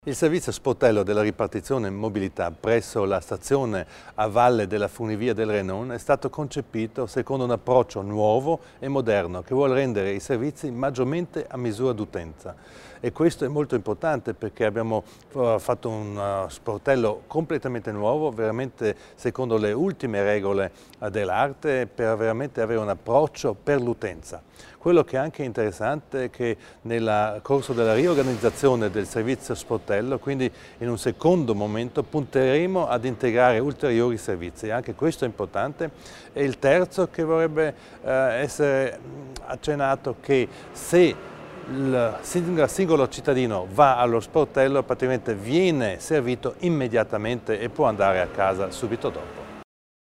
L'Assessore Widmann spiega le novità offerte dal nuovo servizio sportello